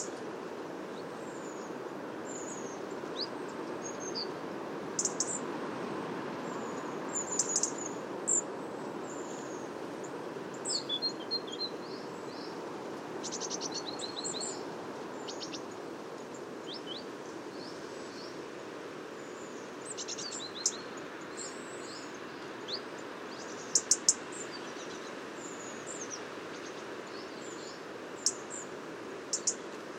rouge-gorge.mp3